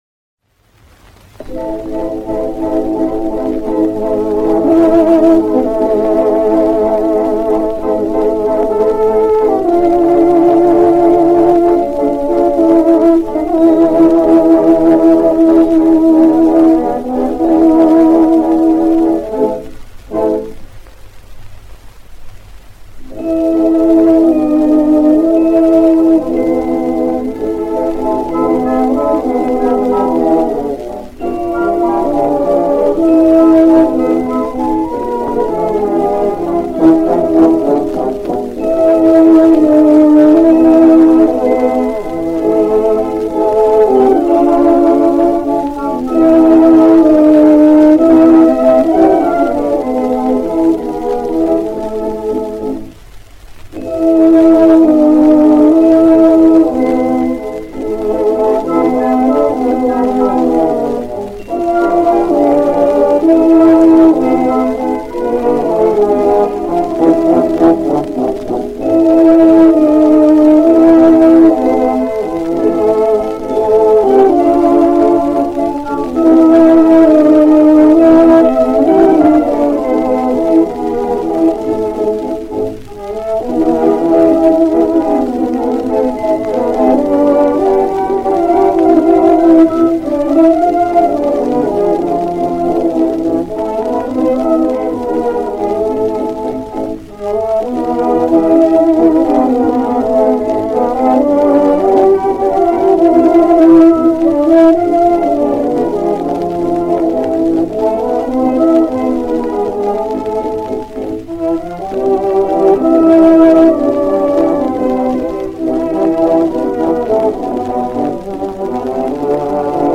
Со старых пластинок с одного из сайтов Па-д эспань, может у кого есть в качестве, именно такое исполнение